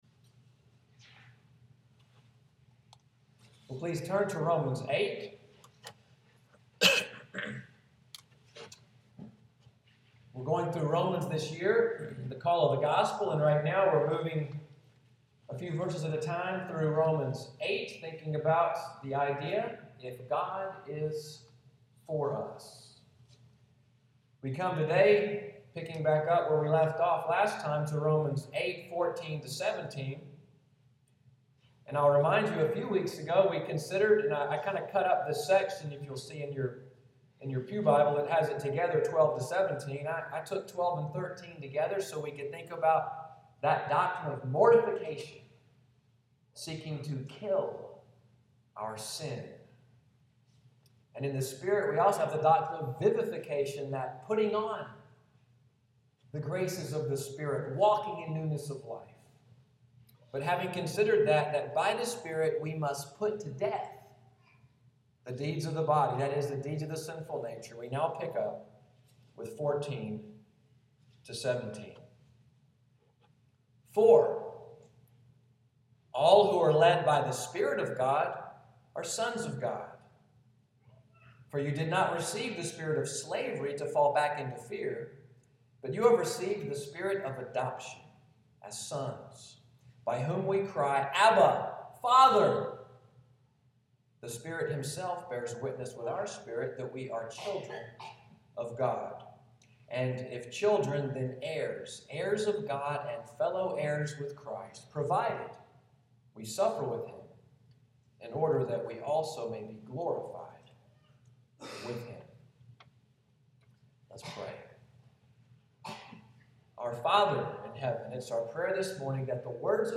Today’s sermon, “Adopted into the Family,” July 26, 2015.